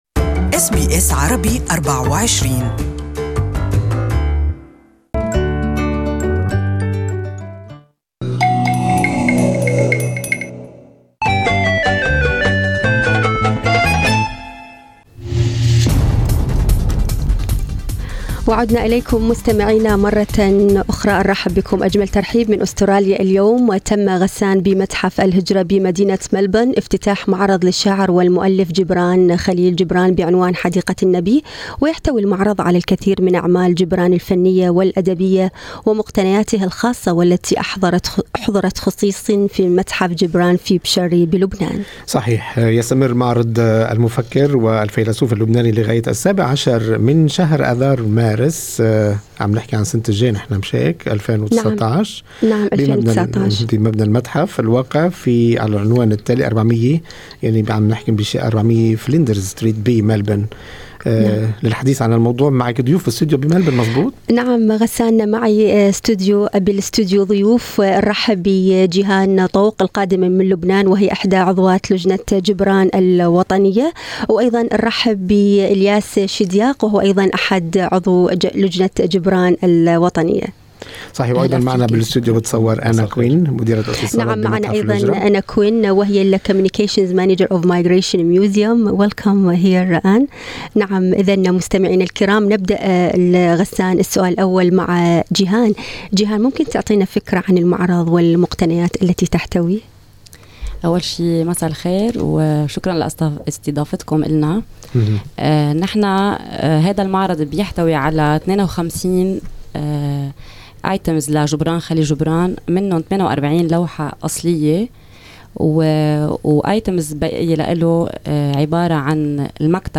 للتعرف اكثر على هذا المعرض يمكن الاستماع الى هذه المقابلة